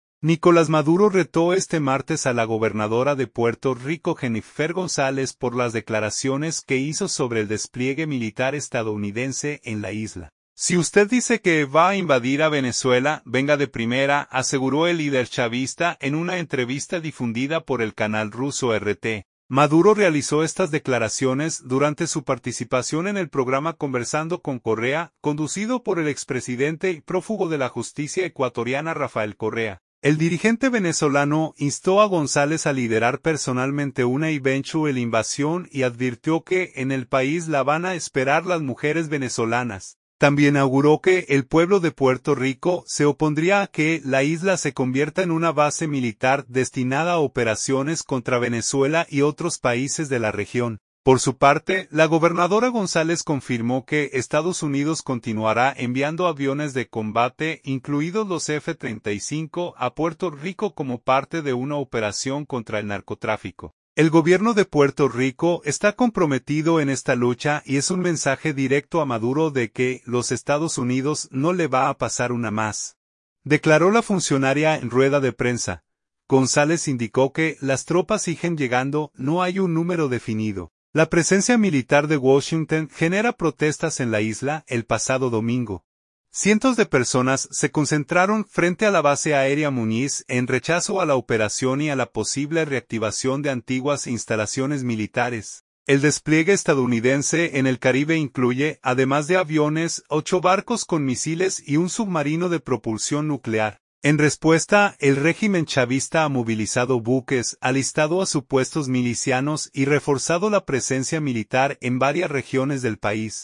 “Si usted dice que va a invadir a Venezuela, venga de primera”, aseguró el líder chavista en una entrevista difundida por el canal ruso RT.
Maduro realizó estas declaraciones durante su participación en el programa Conversando Con Correa, conducido por el expresidente y prófugo de la justicia ecuatoriana Rafael Correa.